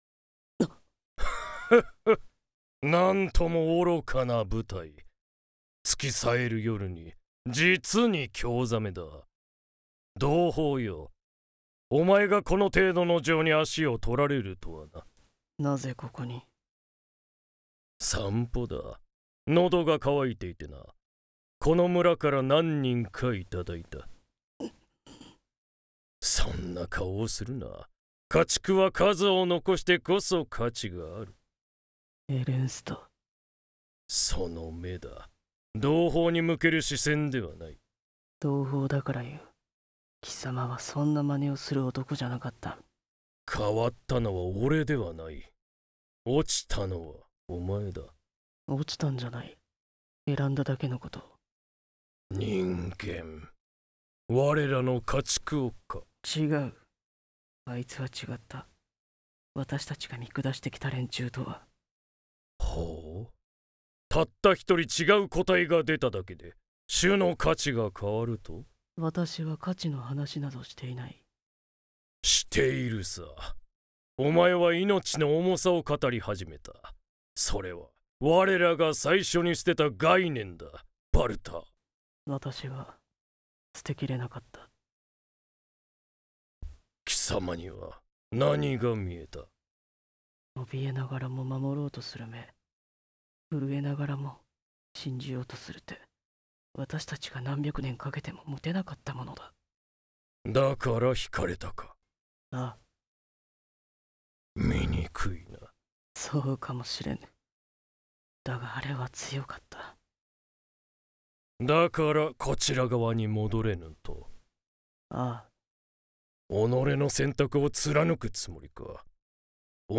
【二人声劇】